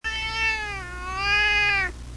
Ling Ling is a male seal point Siamese (Asian short hair) cat born February 18, 1988, in Rubicon WI.